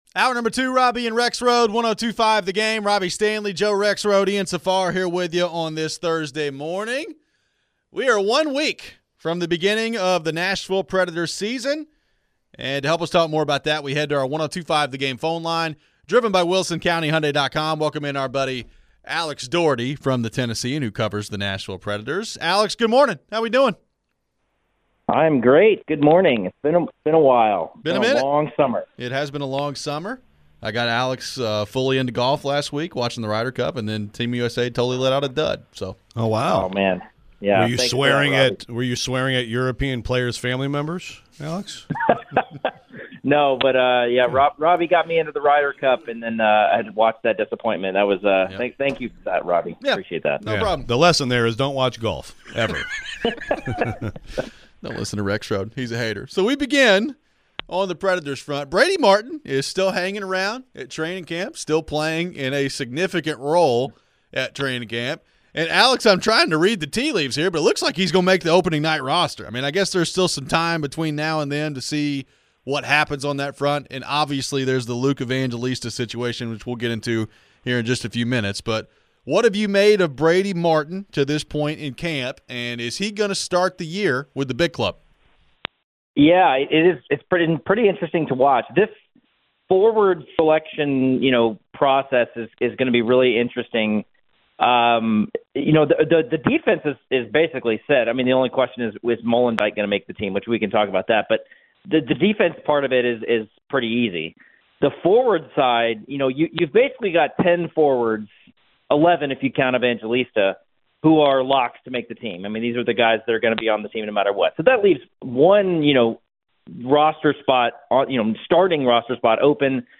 We take your phones. How do we feel about Vandy's chances with the remaining schedule?